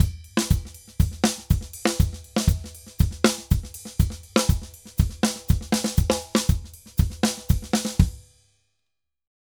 Drums_Merengue 120_3.wav